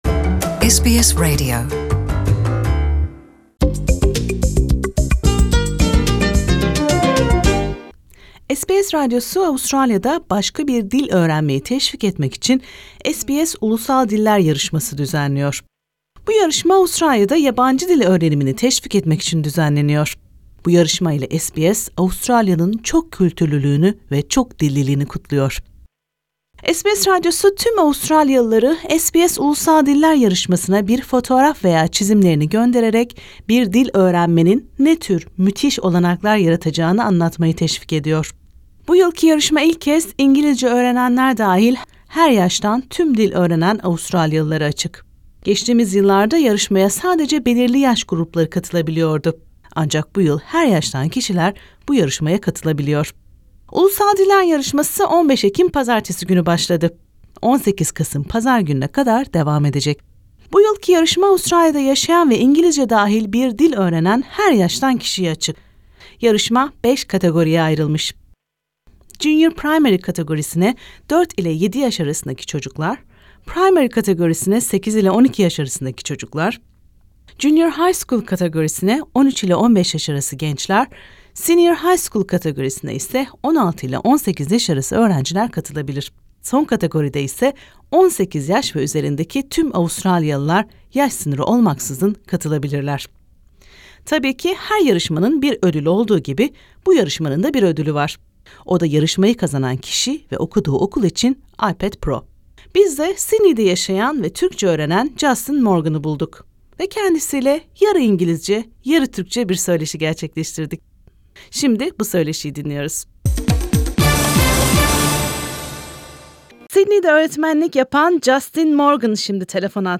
söyleşiyi